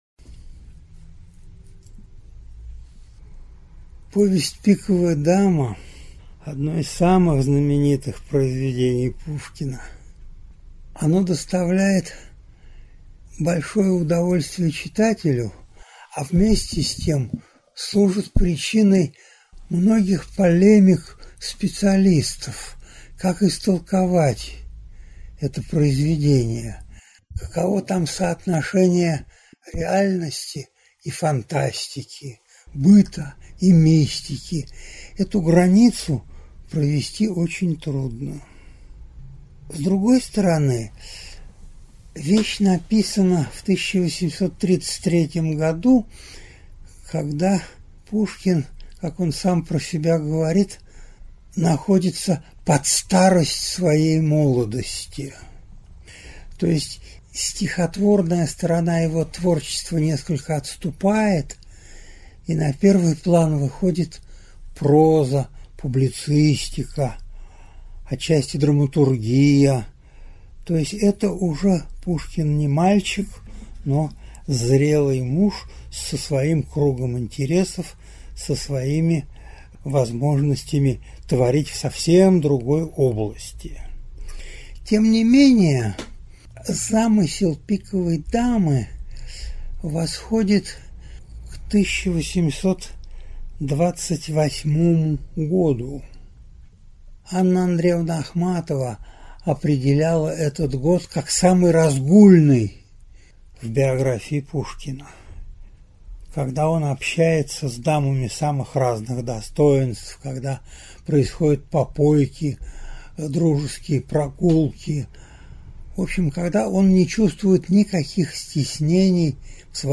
Аудиокнига Загадки повести «Пиковая дама» | Библиотека аудиокниг
Прослушать и бесплатно скачать фрагмент аудиокниги